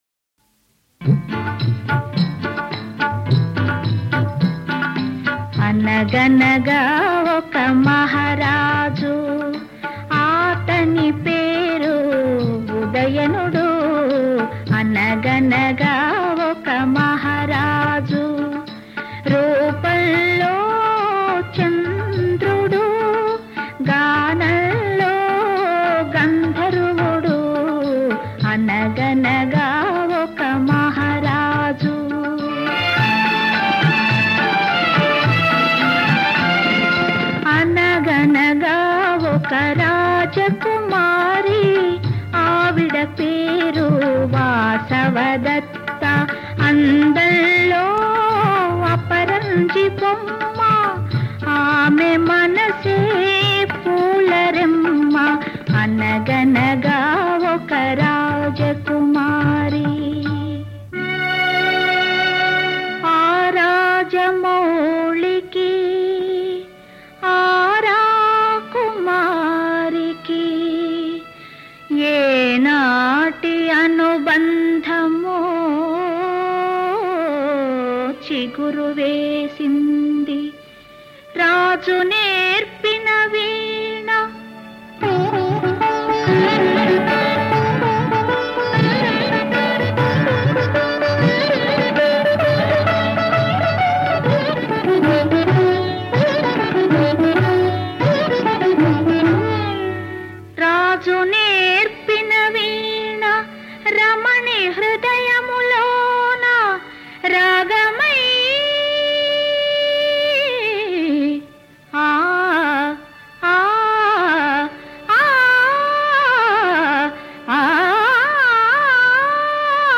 నృత్య నాటకము